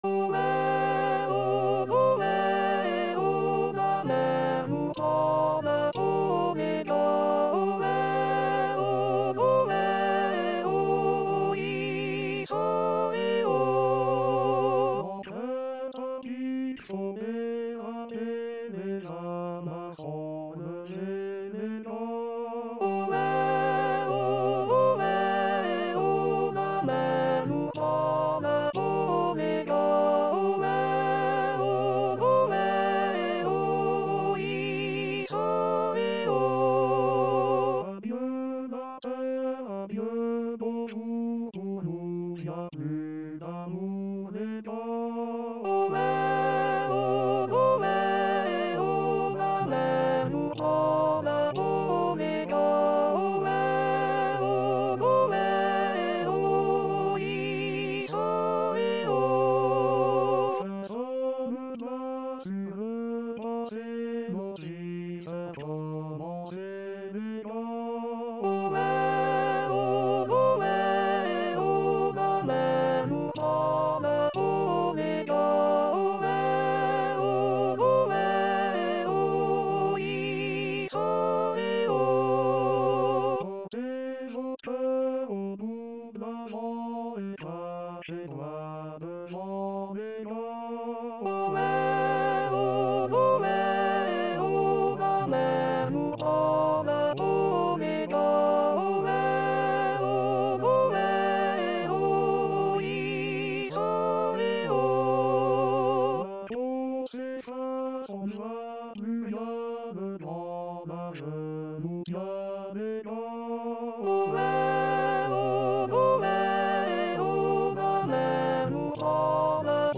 Les abréviations sont inchangées: A = Altis, B= Basses, C = Choeur, F= Femmes,
away_haul_away_a_v.mp3